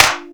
INSTCLAP02-R.wav